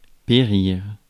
Ääntäminen
Synonyymit mourir Ääntäminen France: IPA: [pe.ʁiʁ] Haettu sana löytyi näillä lähdekielillä: ranska Käännös Ääninäyte Verbit 1. perish US Määritelmät Verbit Prendre fin ; cesser d’ être .